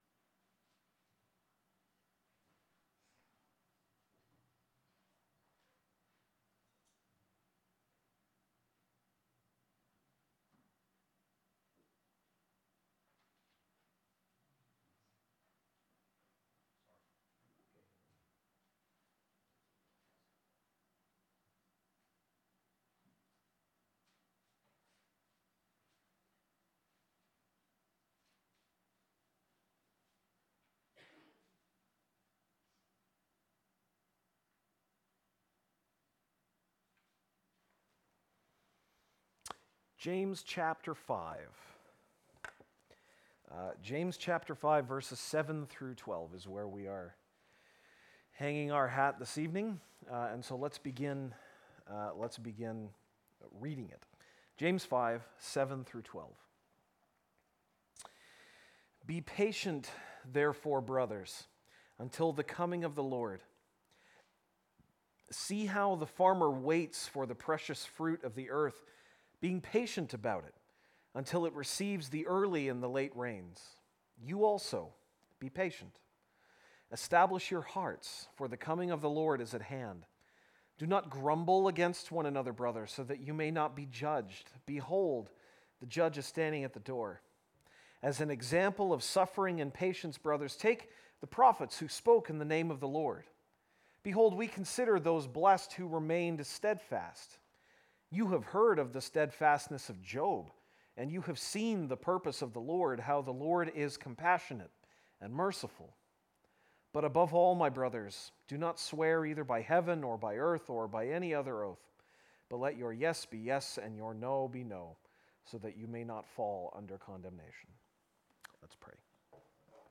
September 11, 2016 (Sunday Evening)